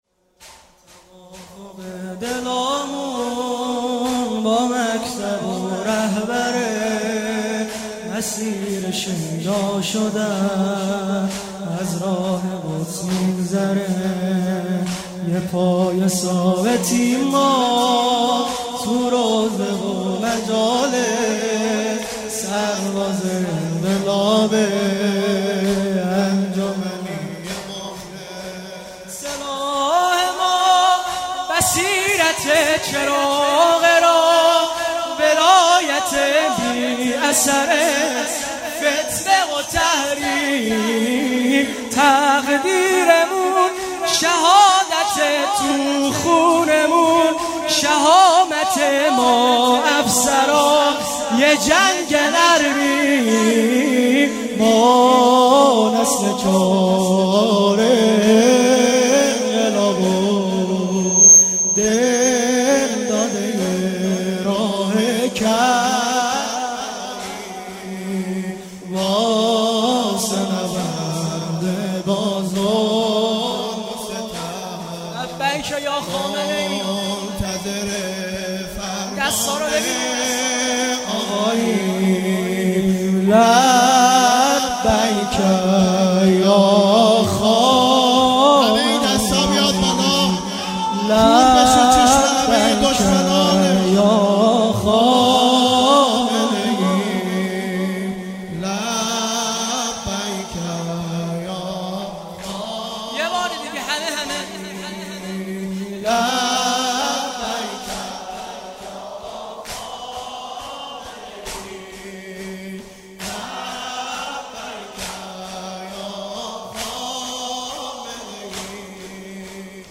خیمه گاه - هیئت دانش آموزی انصارالمهدی(عج)-دارالعباده یزد - سرودپایانی هیات دانش آموزی انصارالمهدی(عج)